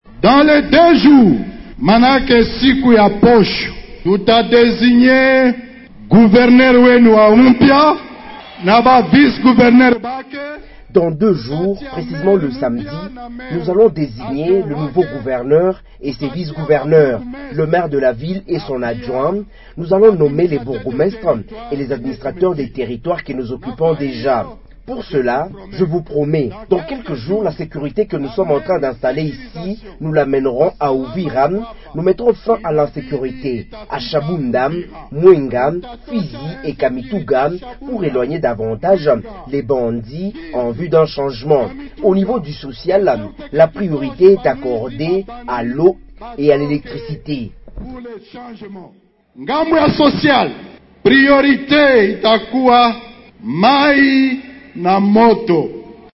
Le coordonnateur de cette plateforme Corneille Nangaa l’a annoncé dans son meeting tenu ce jeudi 27 février 2025 à la place de l’indépendance à Bukavu, chef-lieu du Sud Kivu.
Sur le plan politique, il indique que son mouvement va barrer la route au projet du changement de la constitution tel que voulu par le régime de Kinshasa. Corneille Nangaa dans cet extrait.